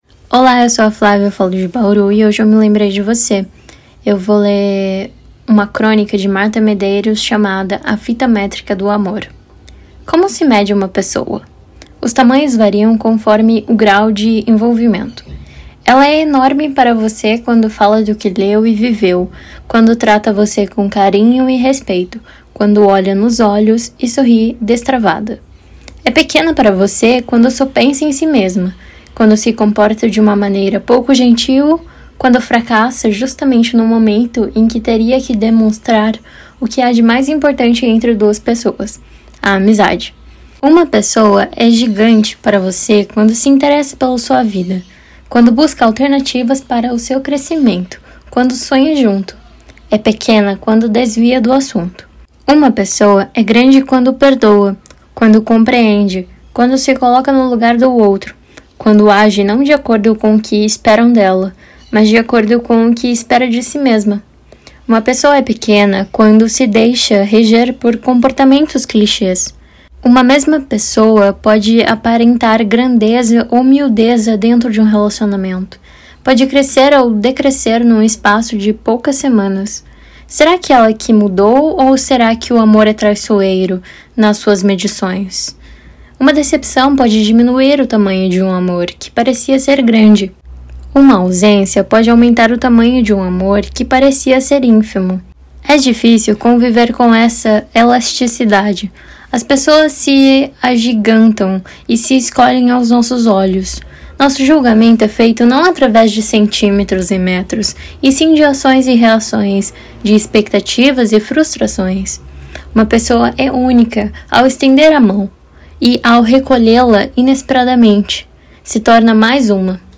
Crônica Português